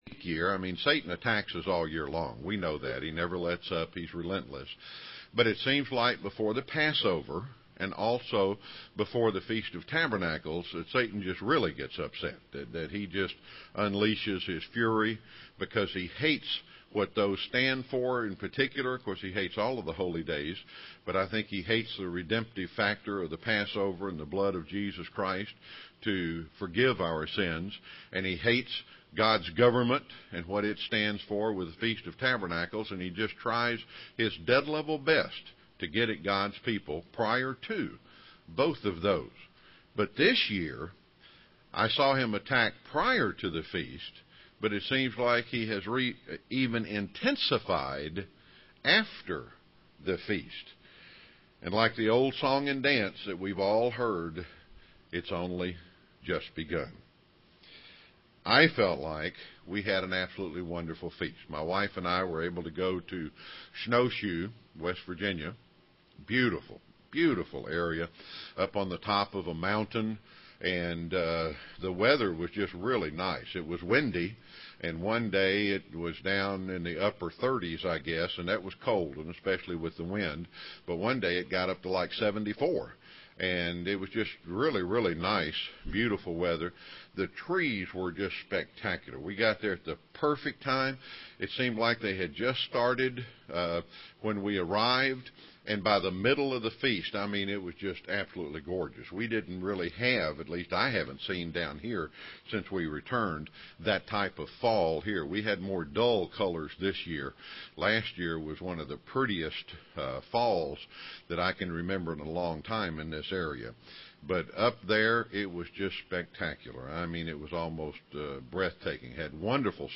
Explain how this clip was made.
Given in Rome, GA